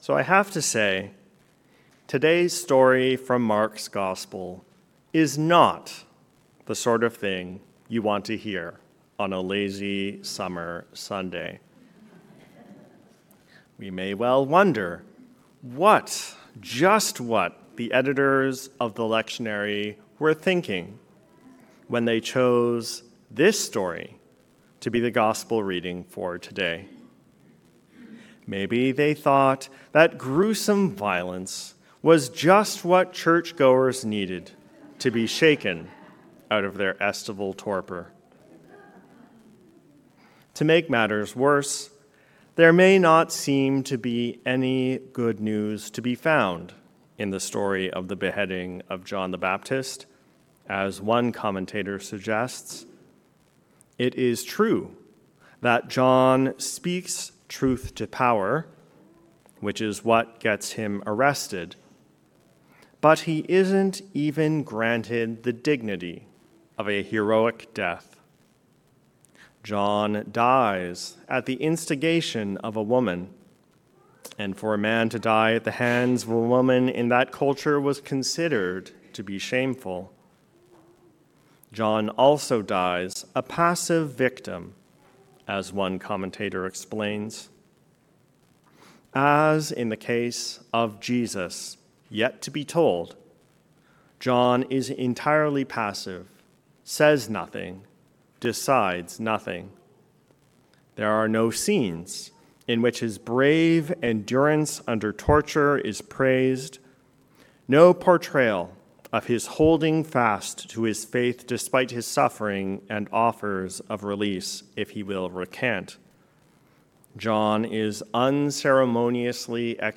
Sermon for the Eighth Sunday after Pentecost